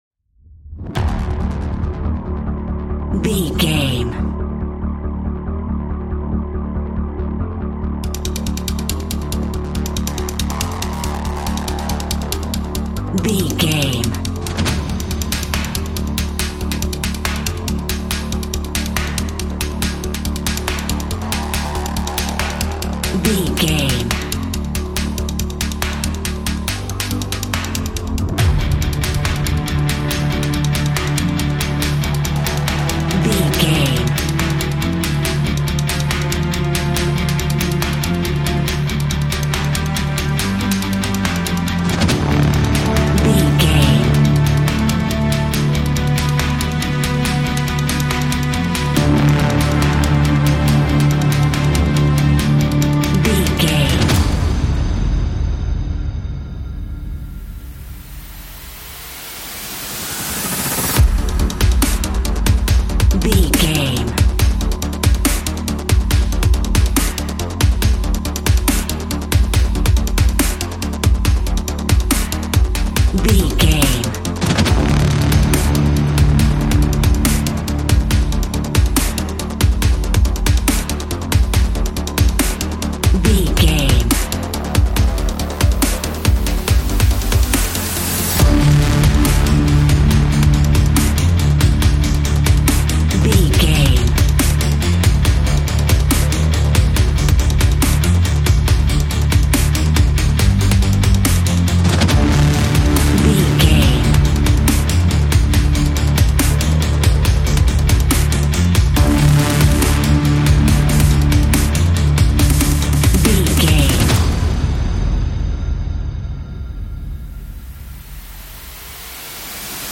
Fast paced
In-crescendo
Aeolian/Minor
industrial
electric guitar
synthesiser